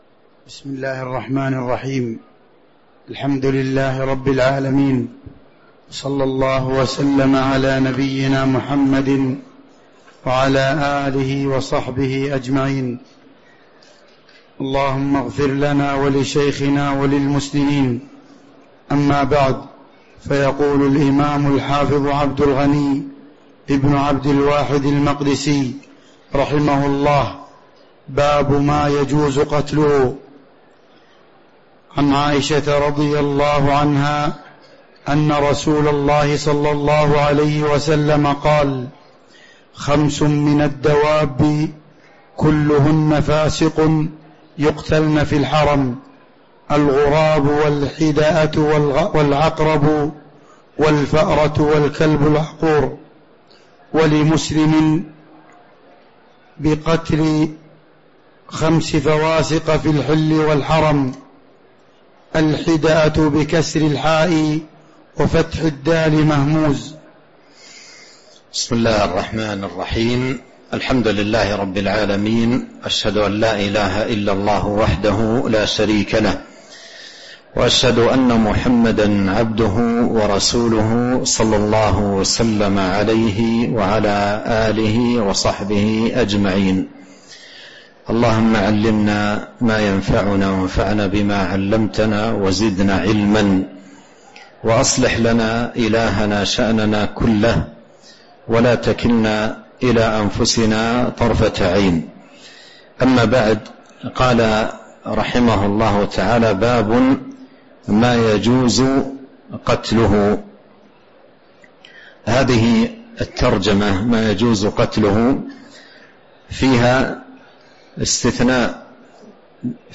تاريخ النشر ١٢ جمادى الآخرة ١٤٤٤ هـ المكان: المسجد النبوي الشيخ